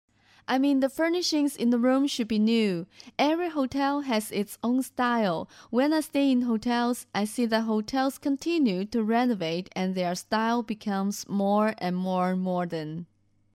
Sprecher chinesisch (Muttersprachler). localization, narration, documentary,advertising etc.
Sprechprobe: eLearning (Muttersprache):